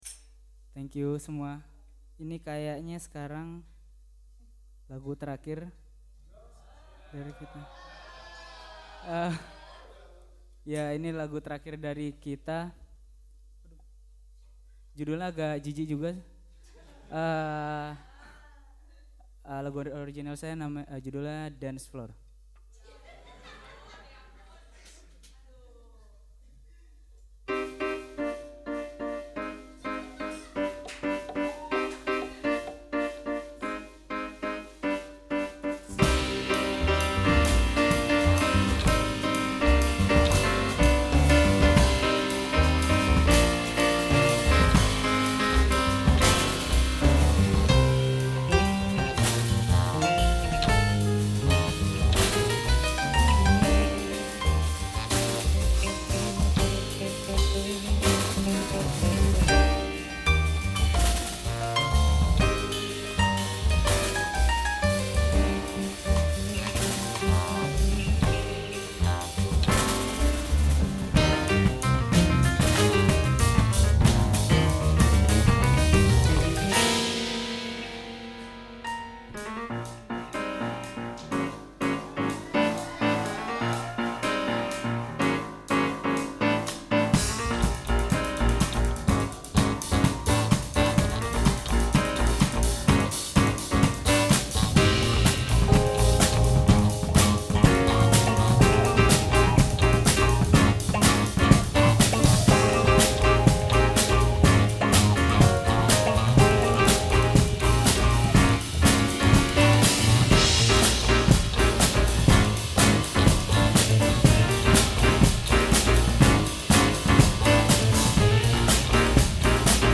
and 2 drummers